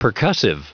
Prononciation du mot percussive en anglais (fichier audio)
Prononciation du mot : percussive